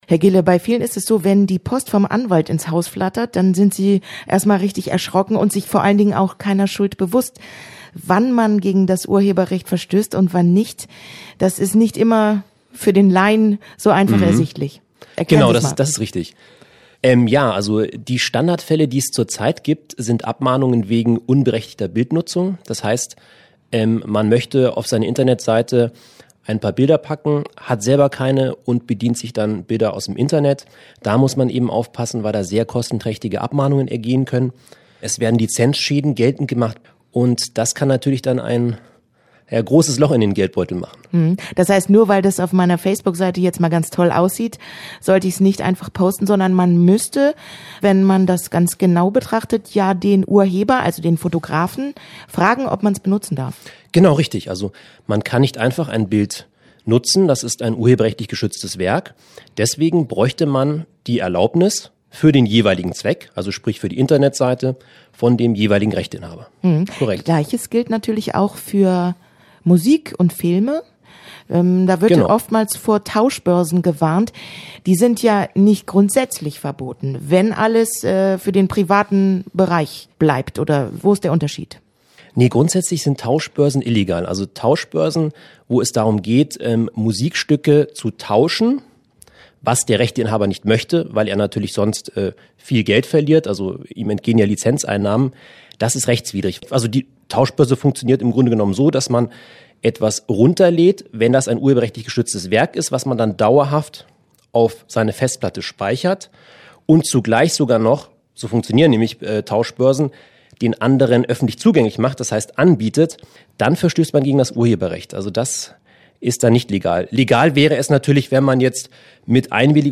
Verbraucherschutz im Gespräch